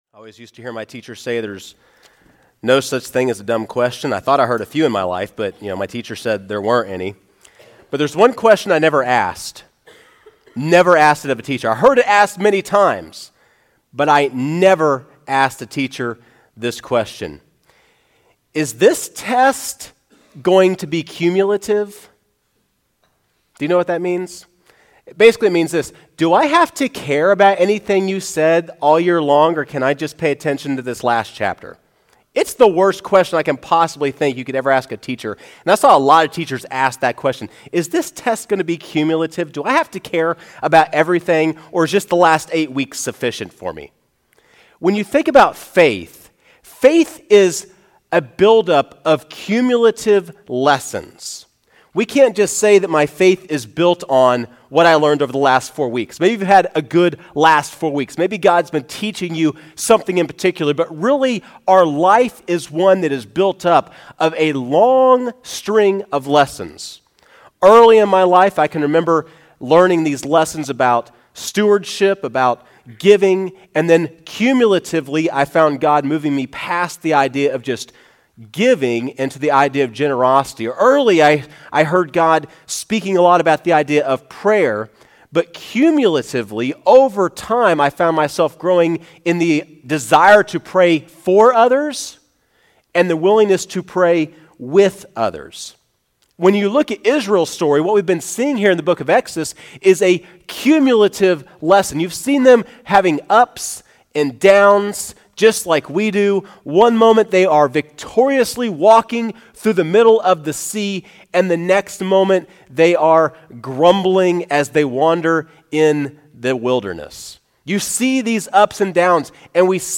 Sermon Audio | First Baptist Church of Machesney Park